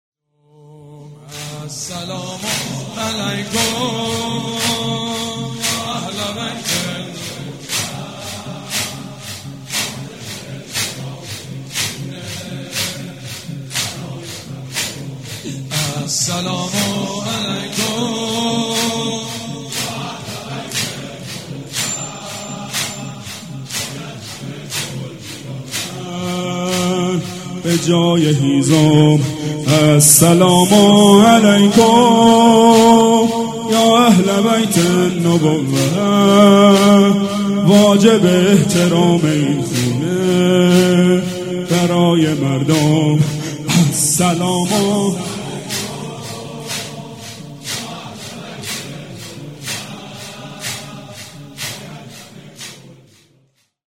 شب چهارم فاطميه دوم١٣٩٤
مداح
مراسم عزاداری شب چهارم